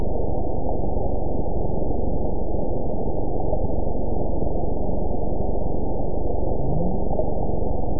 event 912076 date 03/17/22 time 22:13:21 GMT (3 years, 1 month ago) score 8.91 location TSS-AB05 detected by nrw target species NRW annotations +NRW Spectrogram: Frequency (kHz) vs. Time (s) audio not available .wav